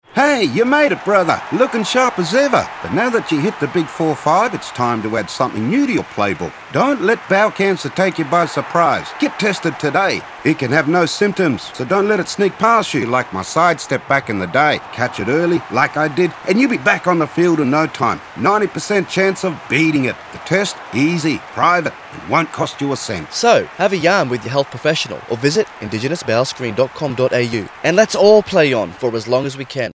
Using humour, pride in local culture, and Creole translations, we’ve created an engaging and accessible campaign.
Whether it’s sharing the importance of bowel cancer screening or reminding listeners about their health check-ups, we’ve made sure the tone reflects the spirit of the community—inviting, approachable, and relatable.